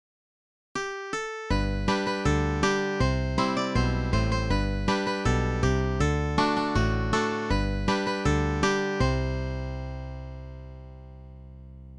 (traditional fiddle tune and novelty song,